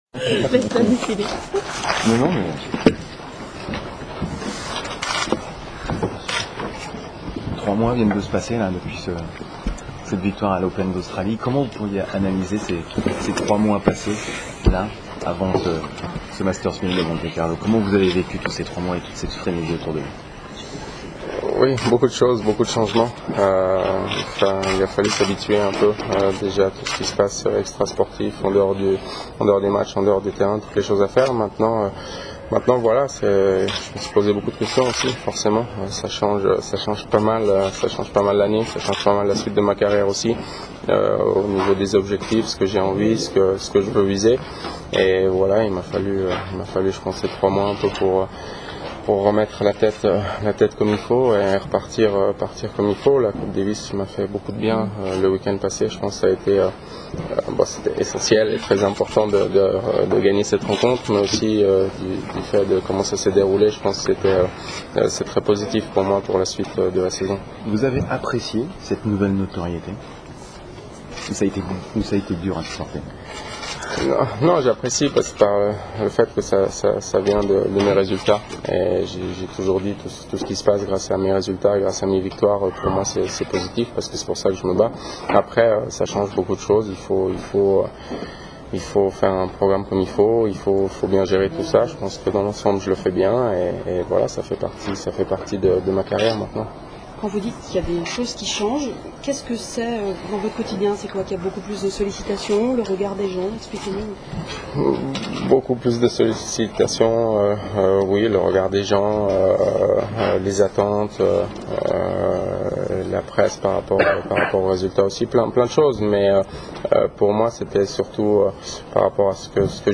TENNIS ATP MONTECARLO – I “Big” della racchetta incontrano i giornalisti in una conferenza stampa pre-torneo seduti ai tavoli bianchi del Villaggio Vip.